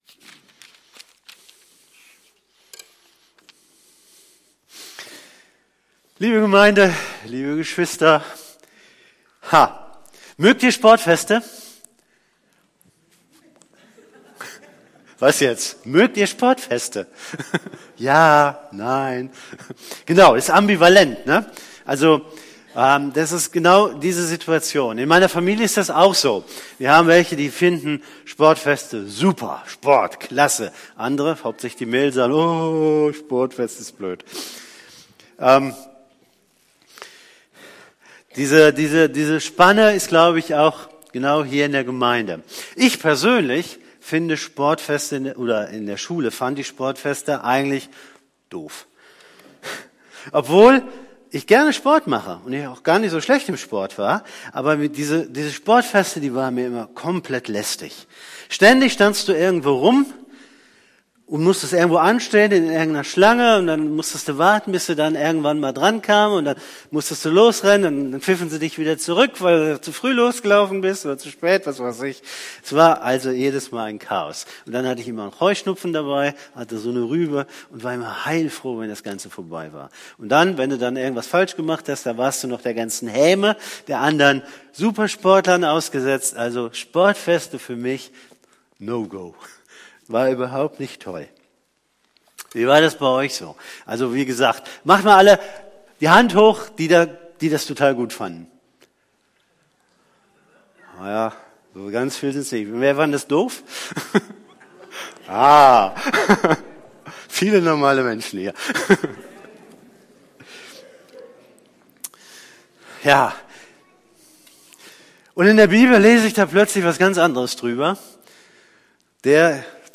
Predigt
predigt.mp3